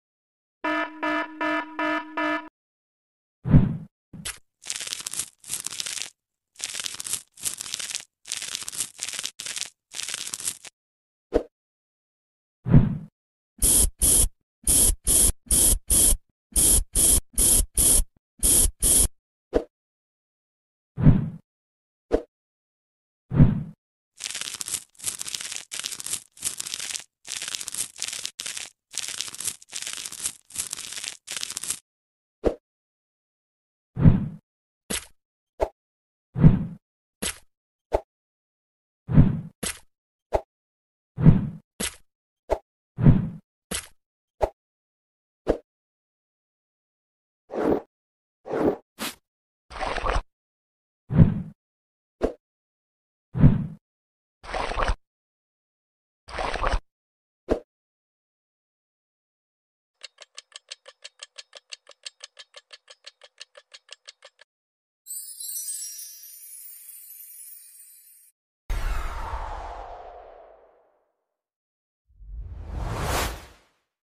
ASMR treatment from infected eye